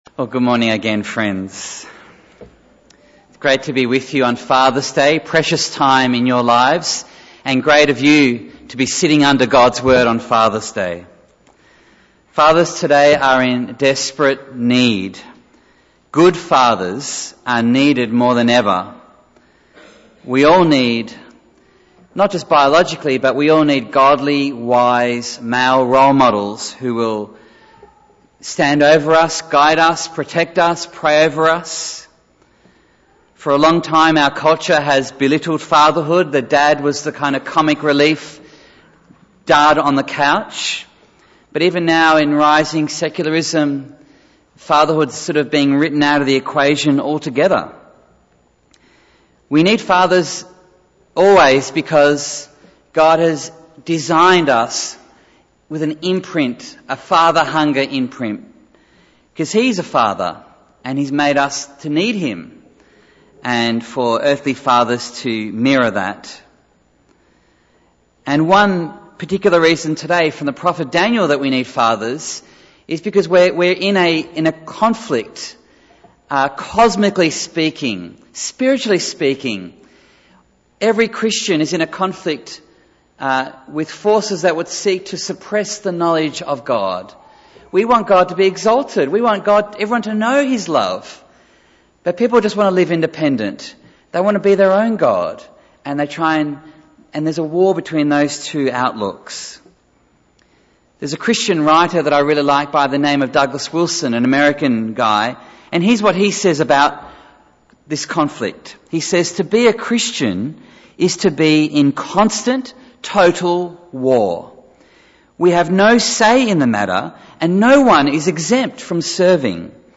Bible Text: Daniel 8:15-27 | Preacher